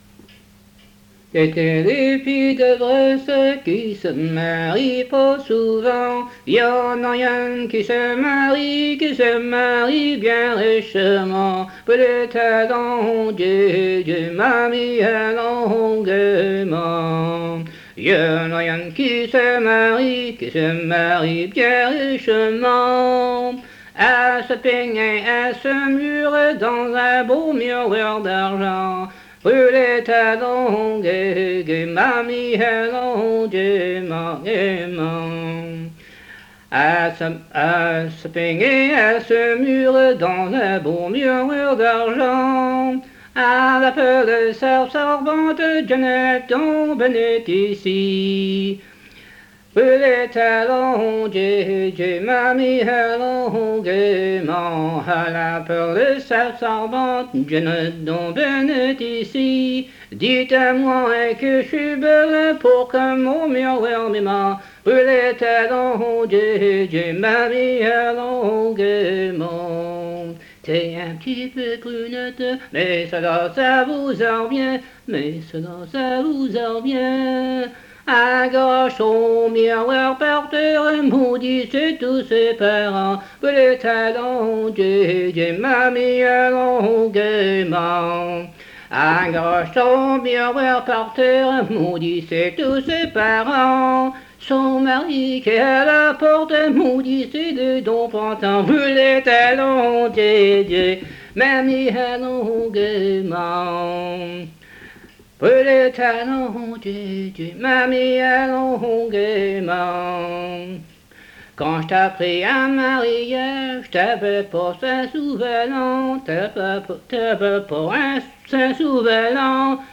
Emplacement La Grand'Terre